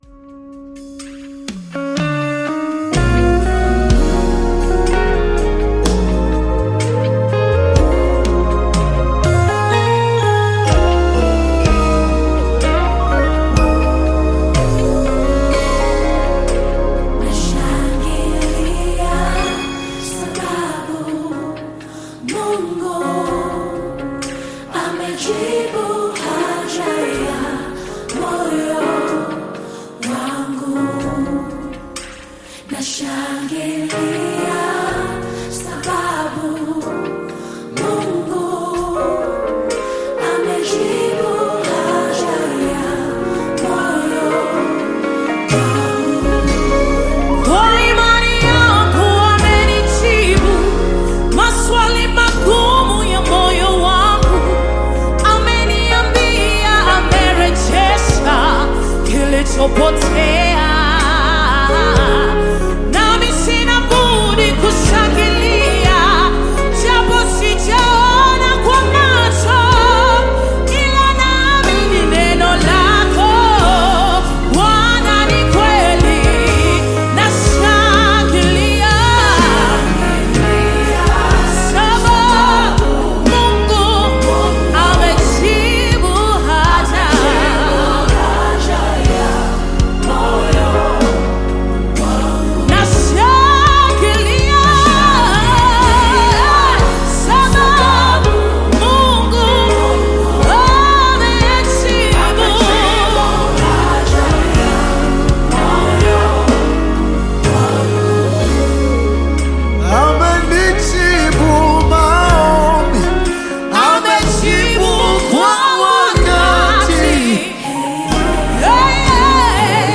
Praise Nyimbo za Dini music song
Praise Gospel music track